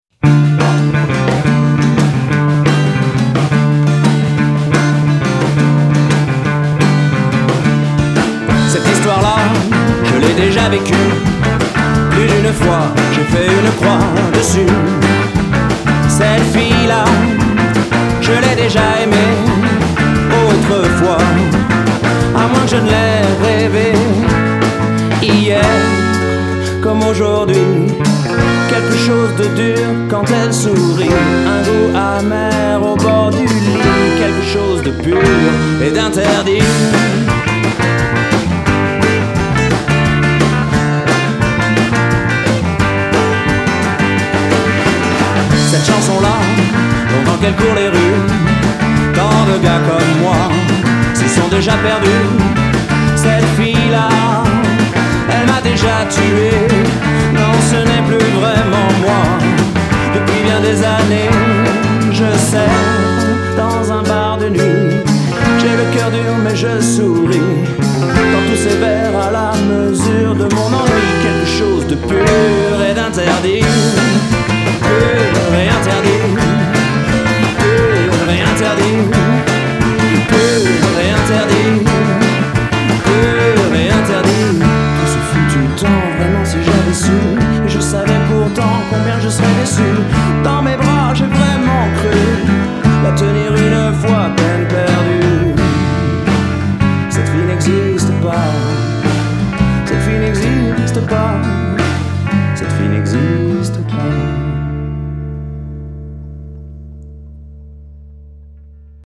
enregistré en 2006 au studio du moulin
guitare, chant
basse
batterie